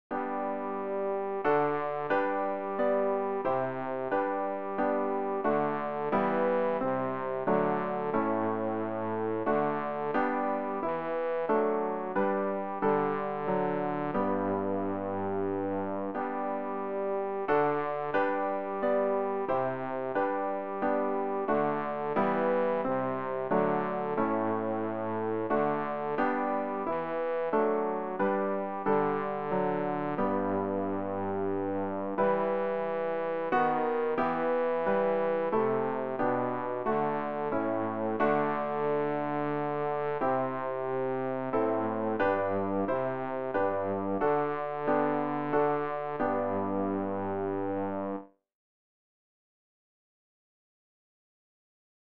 rg-566-gott-des-himmels-bass.mp3